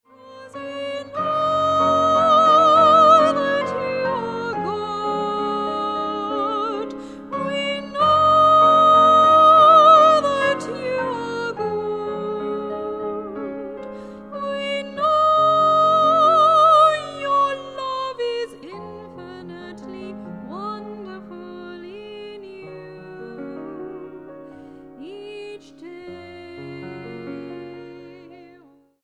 STYLE: Jazz